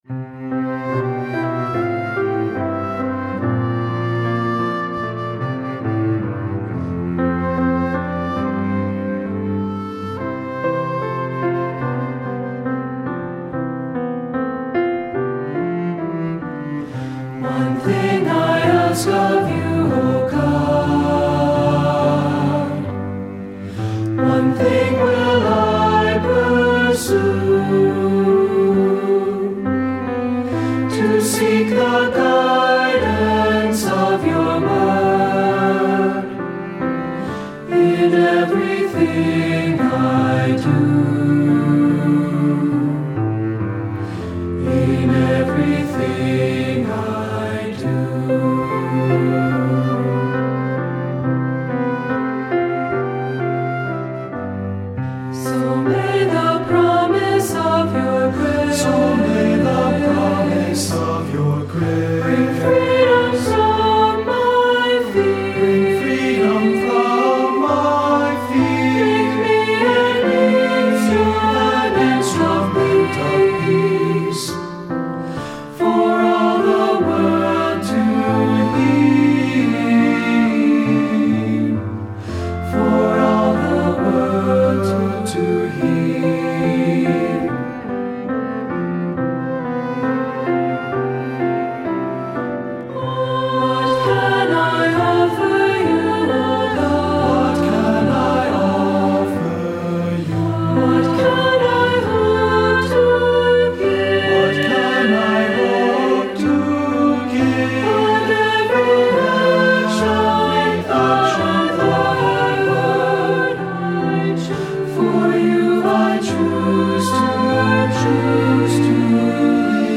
Voicing: Two-part mixed; Three-part mixed; SAB; Assembly